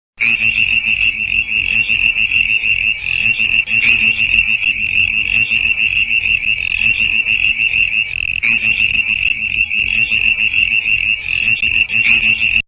There are eight frog species across the Fleurieu Peninsula including the wide spread and common Southern Brown Tree Frog which can often be
heard near rivers and other water bodies.
brown-tree-frog-call-gen.mp3